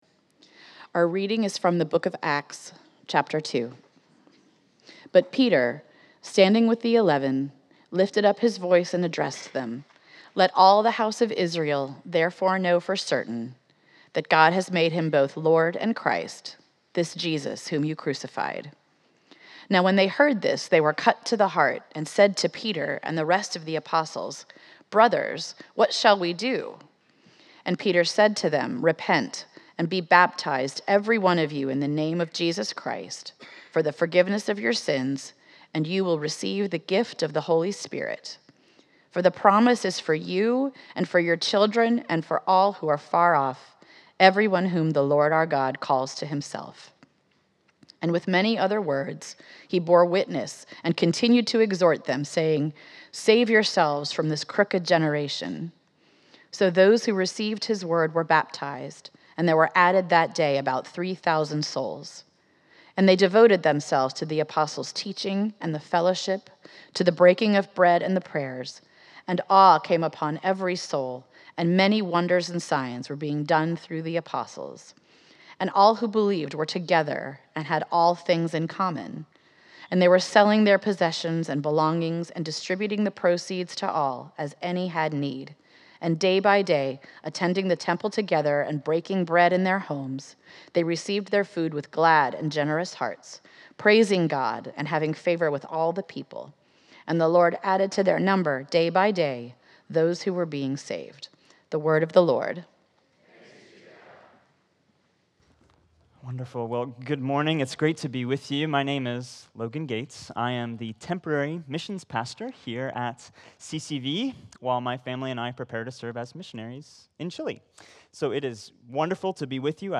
Exploring the origins of the church in Acts 2, this sermon explains how the Christian faith rests on the historical reality of Jesus’ resurrection and a personal, heart-level encounter with the gospel message. It describes how this foundation creates a spirit-filled community characterized by radical generosity, devoted learning, and a commitment to sharing God’s love with the world.